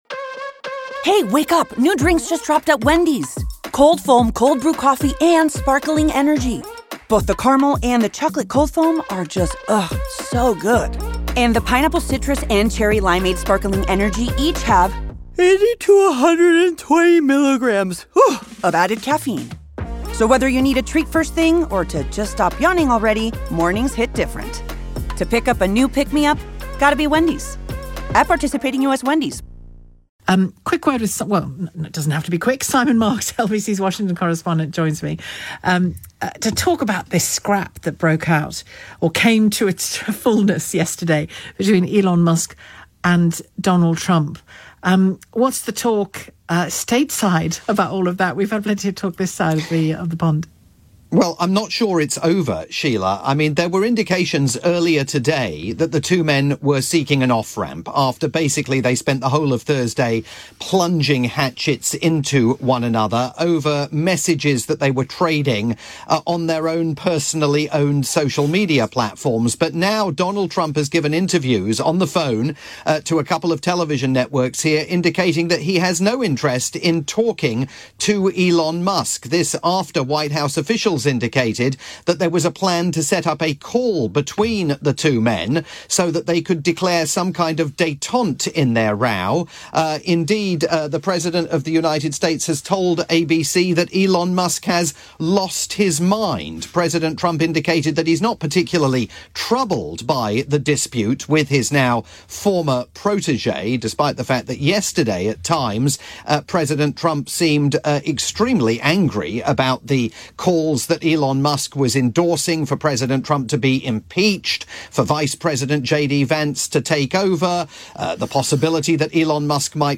live update for Shelagh Fogarty's afternoon programme on the UK's LBC.